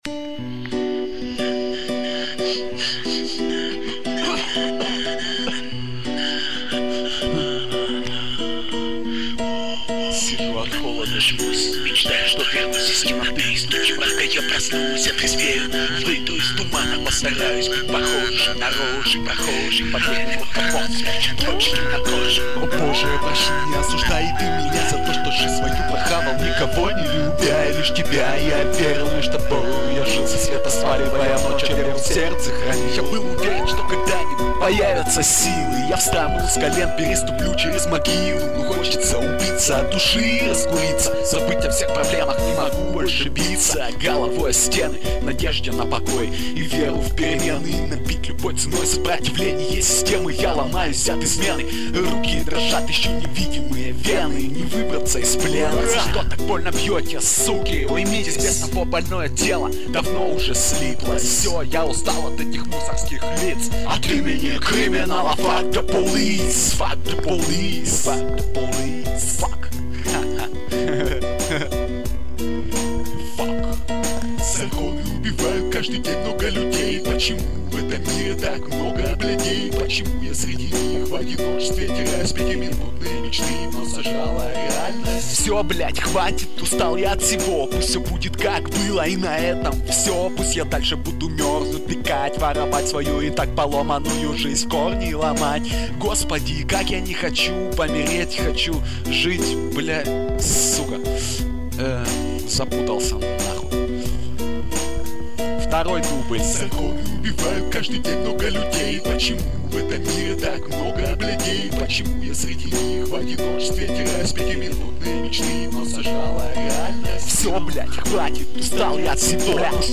2005 Рэп Комментарии